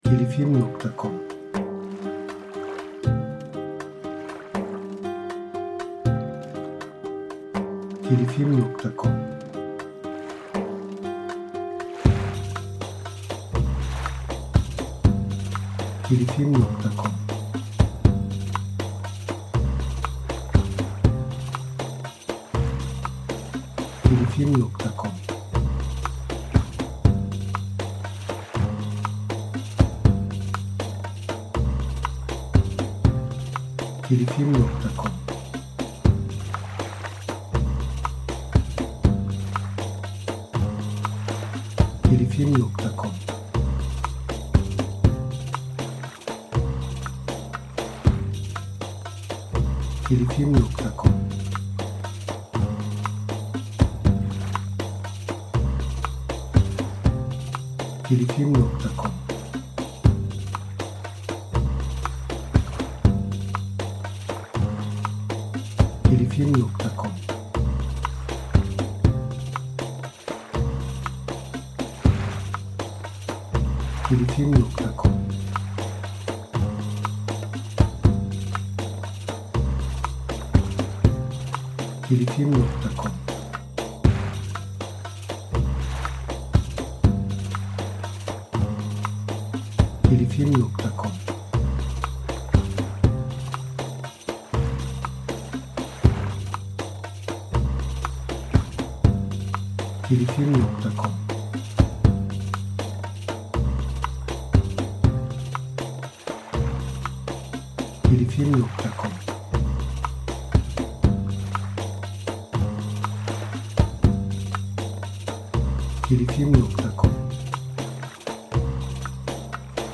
Eser Türü : Müzikal Tema Eser Tipi : Enstrümental